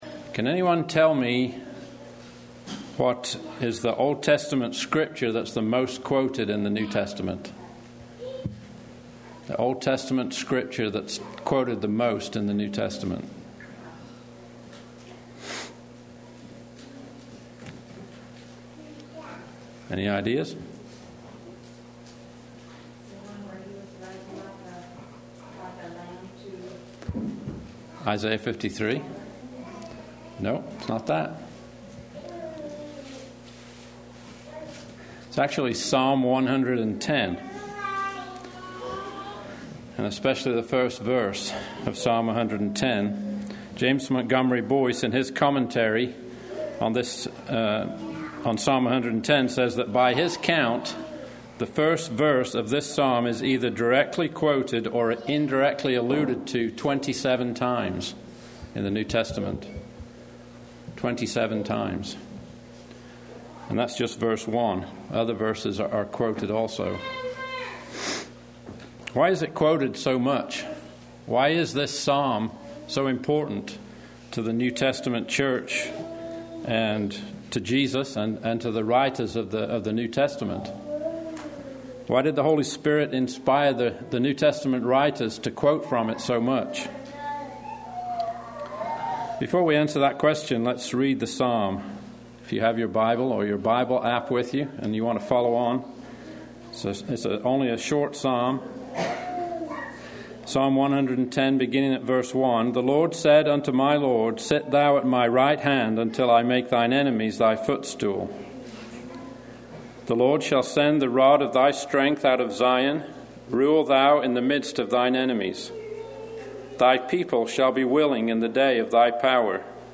A Wednesday night prayer meeting talk